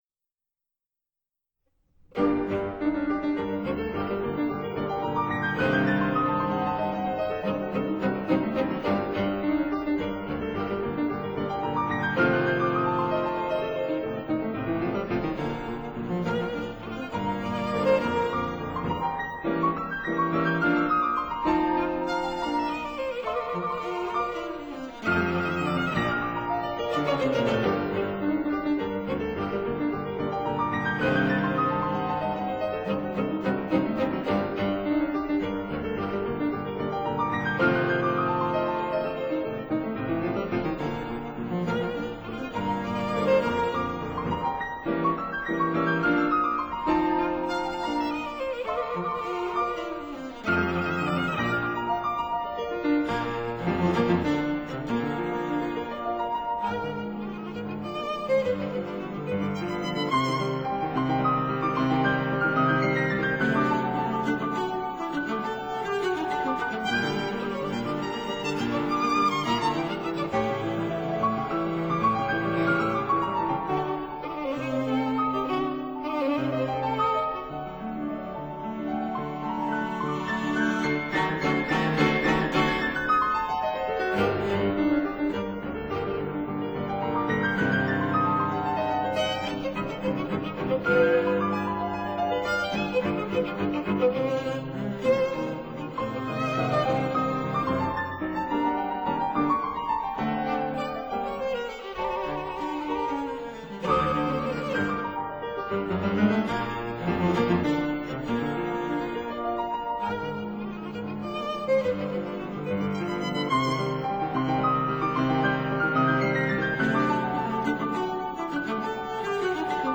•(01) Piano Trio No. 5 in E major, Op. 14 No. 2
piano
violin
cello